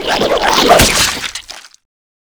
Claw.wav